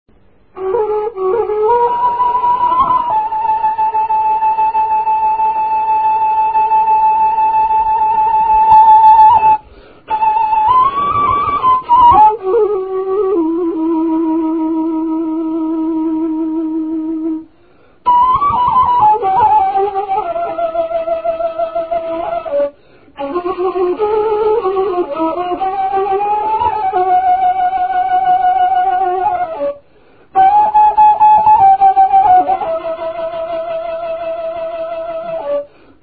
музикална класификация Инструментал
размер Безмензурна
фактура Едногласна
начин на изпълнение Солово изпълнение на кавал
фолклорна област Североизточна България
начин на записване Магнетофонна лента